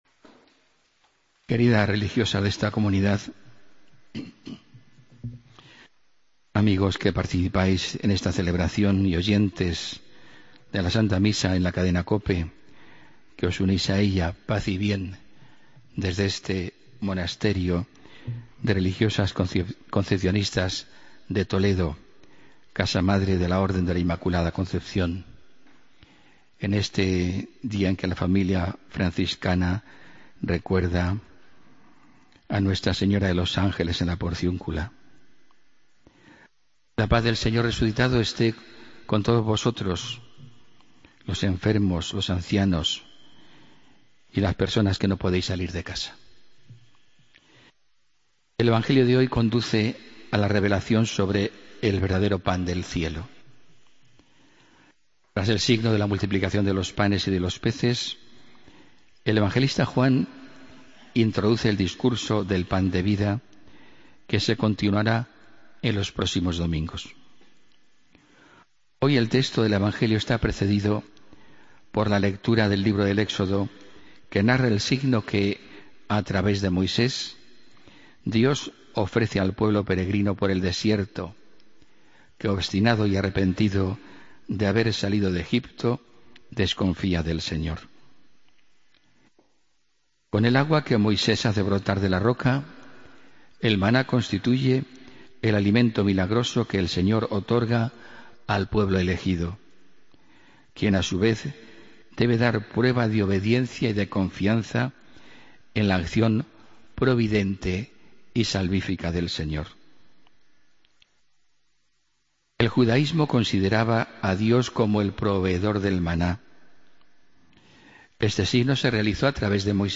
Homilía del domingo 2 de agosto de 2015